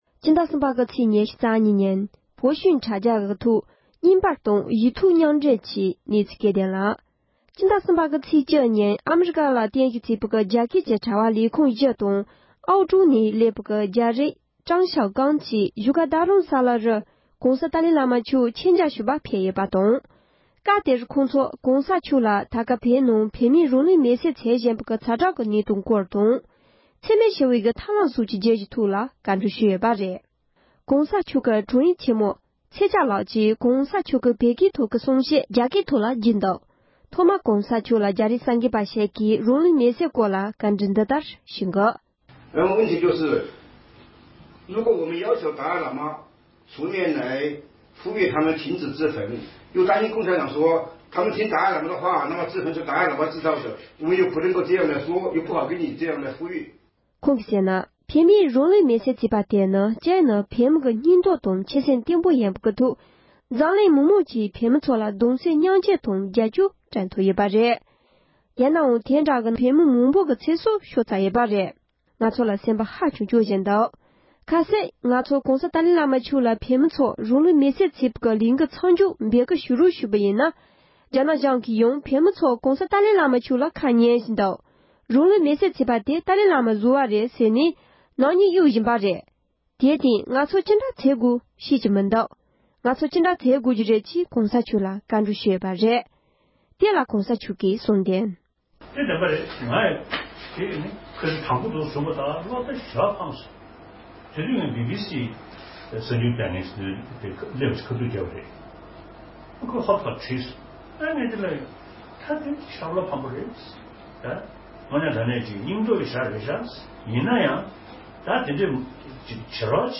རྒྱ་རིགས་གསར་འགོད་པ་ཁག་ཅིག་གིས་༸གོང་ས་མཆོག་ལ་རང་ལུས་མེ་བསྲེག་དང་འབྲེལ་བའི་སྐོར་བཀའ་འདྲི་ཞུས་པ།
སྒྲ་ལྡན་གསར་འགྱུར།